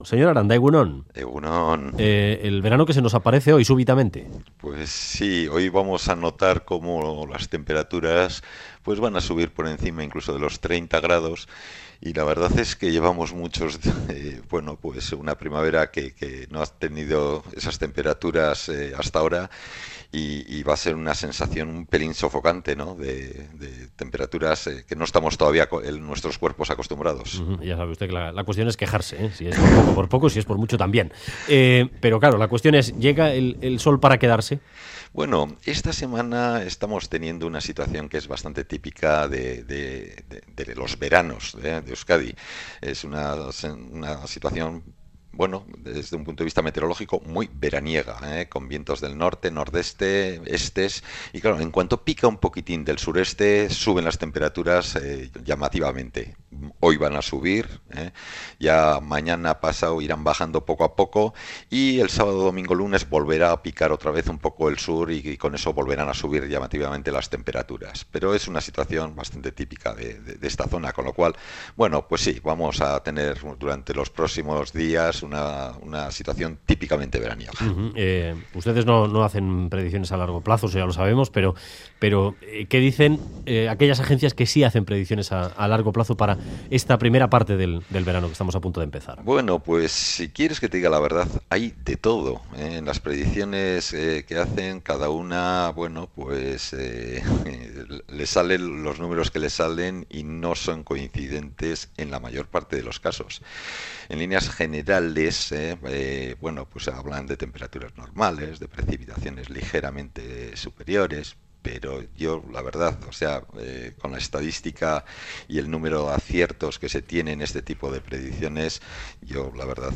A punto de comenzar el verano, entrevistamos